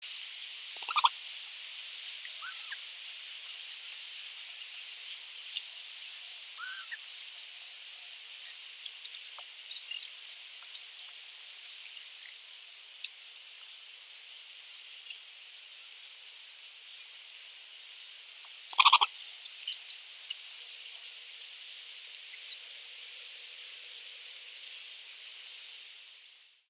Звуки большой выпи
Голос самки в состоянии покоя